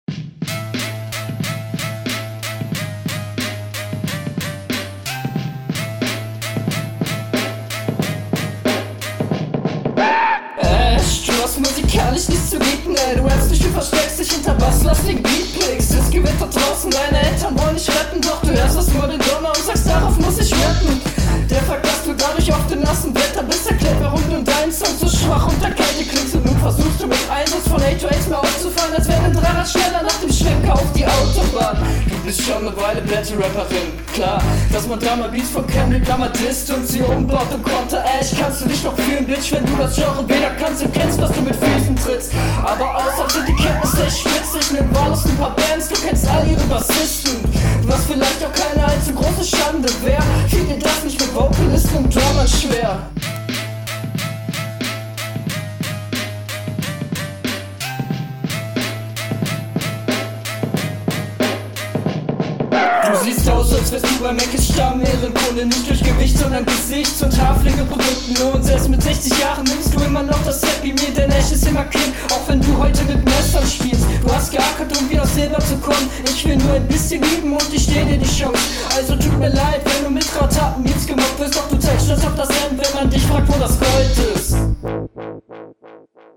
damn is seeeehr cool stimmeinsatz und text sehr cool leider audio quali sehr ass mag …